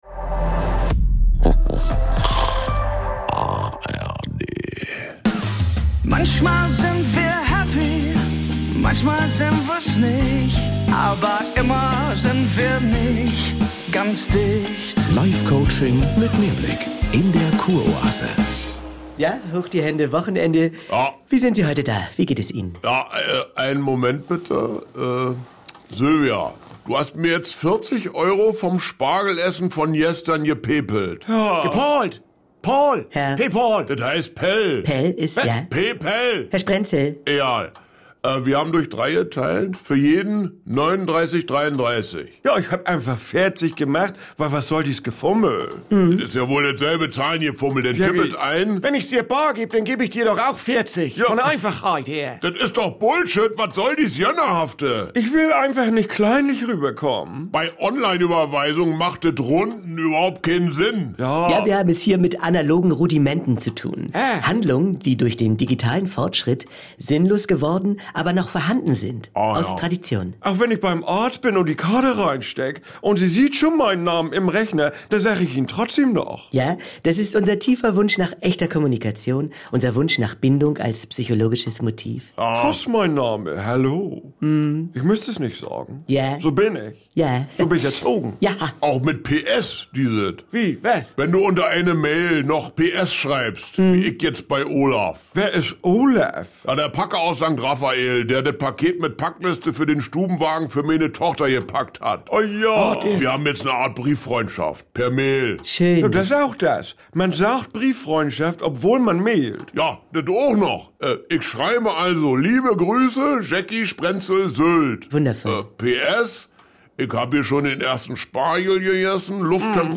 Komödie NDR